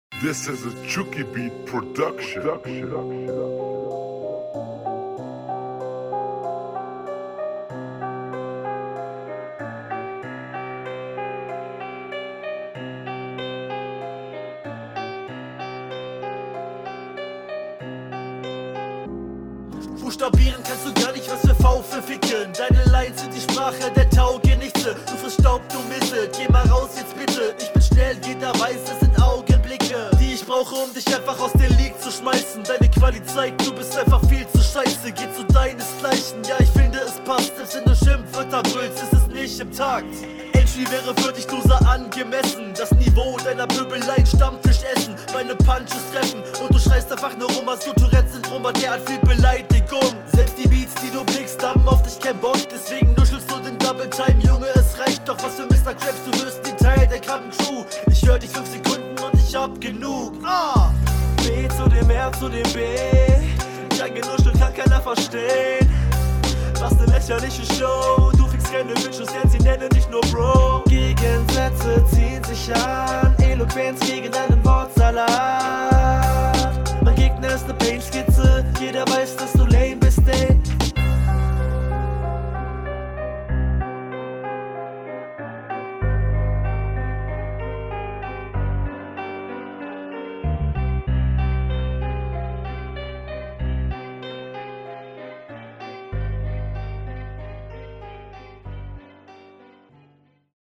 Also die Soundqualität ist perfekt. Finde die Variationen und Flowpassagen in verschiedenen Styles gut.
Schöner Einstieg mit dem Stutter-Effekt, sowas ist immer schön anzuhören.